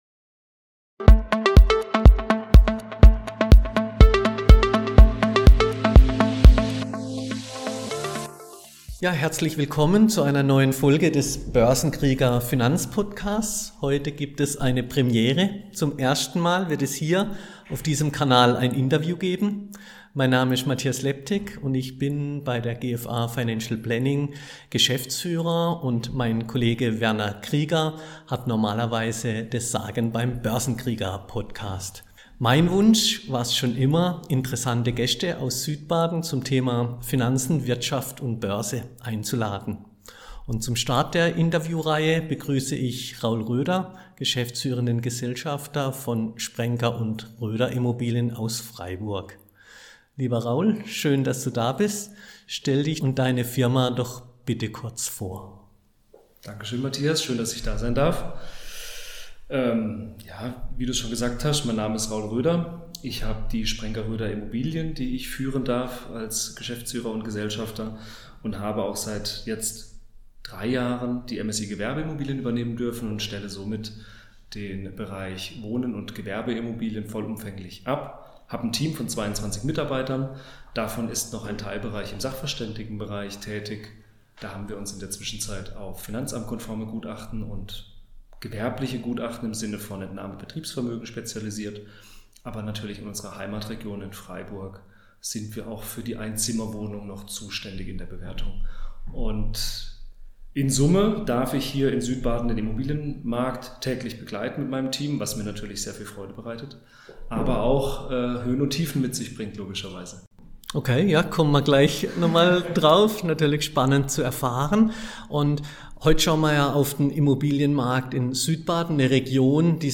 Ein Gespräch voller Insiderwissen, klarer Einschätzungen und konkreter Handlungsempfehlungen für Anleger.